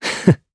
Esker-Vox_Happy1_jp.wav